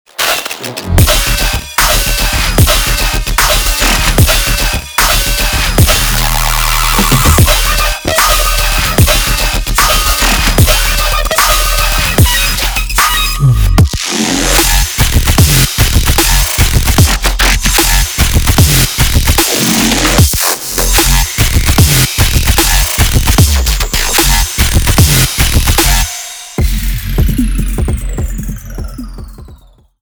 Encoder for Serum (Dubstep Bass Serum Presets)